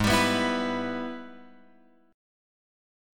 G# Minor Major 7th Sharp 5th